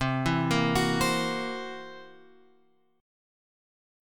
C 7th Flat 5th